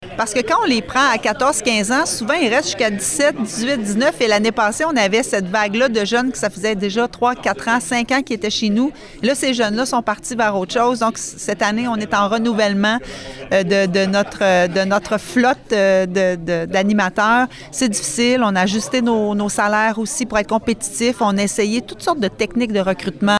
Il y a encore plusieurs postes affichés sur le site de la Ville et comme l’a expliqué la mairesse, Geneviève Dubois, lors du conseil municipal de lundi, ce problème est dû à un cycle lié à l’âge des candidats.